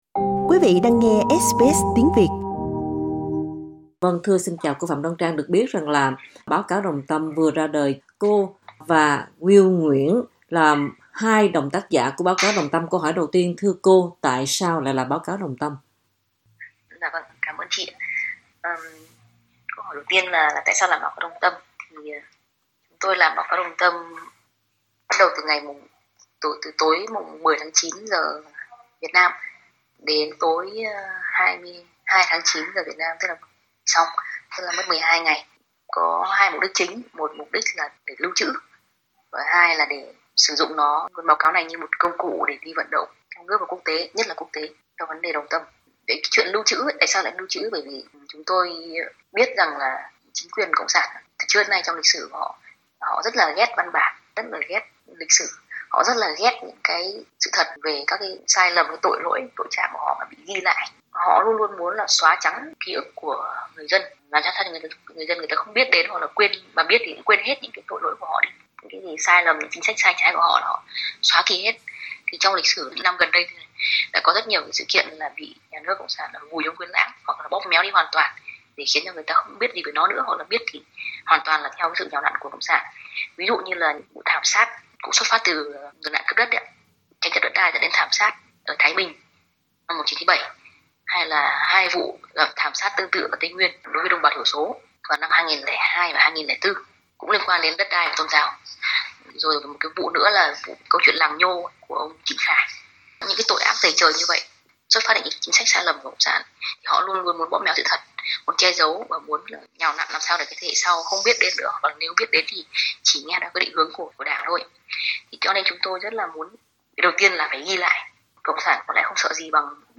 Nói với SBS Việt ngữ, Phạm Đoan Trang - một trong hai tác giả của bản báo cáo cho biết, mục đích của báo cáo là ghi lại một cách trung thực những sự kiện đã xảy ra liên quan đến vụ Đồng Tâm.